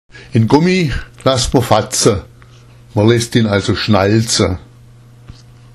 schwäbisch
Alle blauen Begriffe sind vertont und können angehört werden.